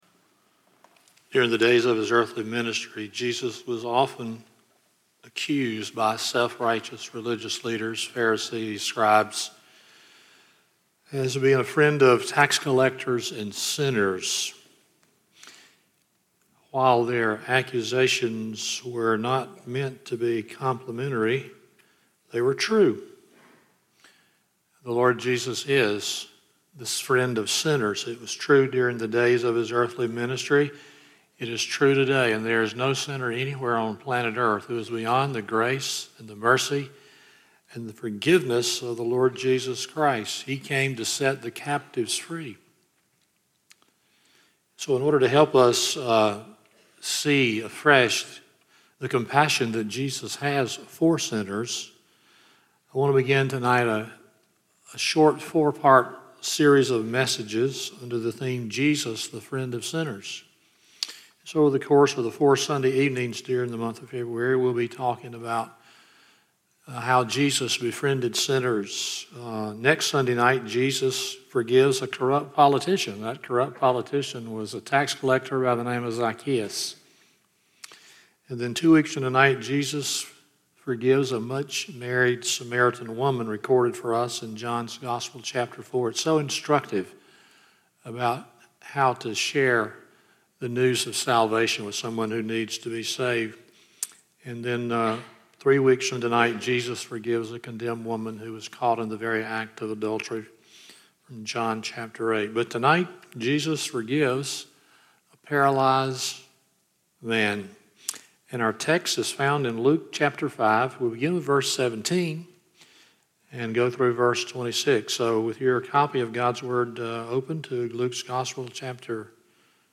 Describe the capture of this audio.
Luke 5:17-26 Service Type: Sunday Evening 1.